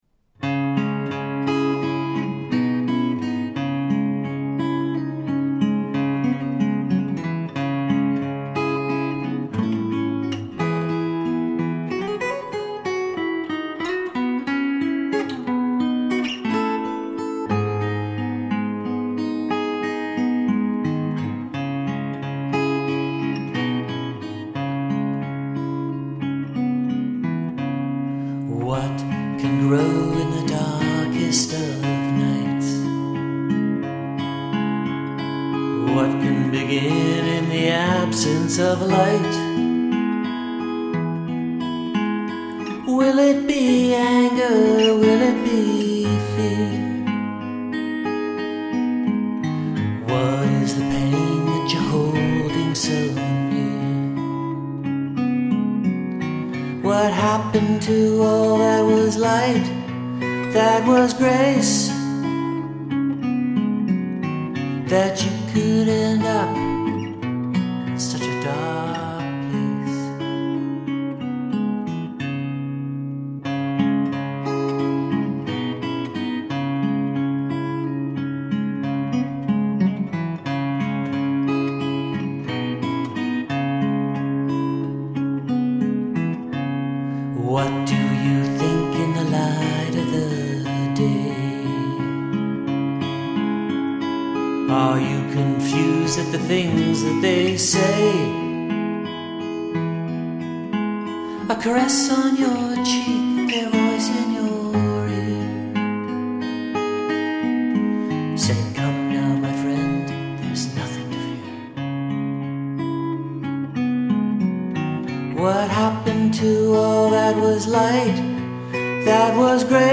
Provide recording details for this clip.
music Music All the music was written and recorded by myself. Guitars are played by me, and I sing.